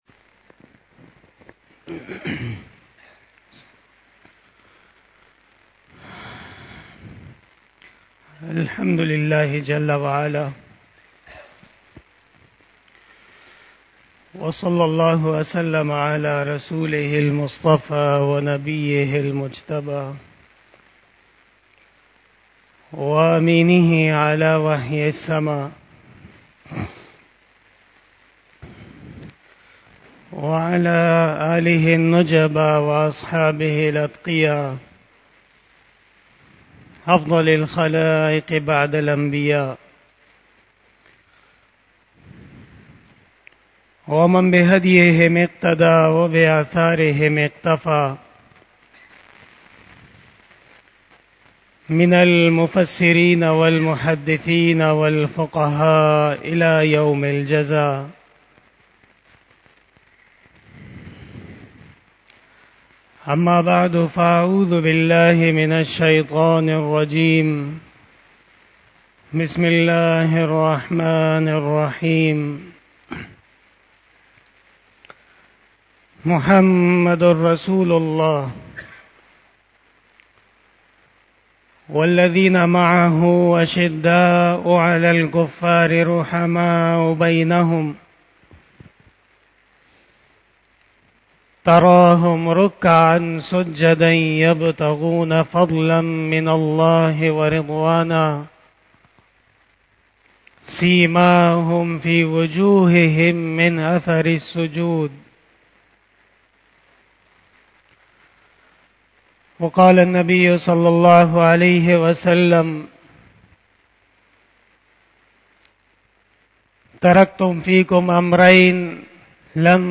35 BAYAN E JUMA TUL MUBARAK 09 September 2022 (11 Safar 1444H)
Khitab-e-Jummah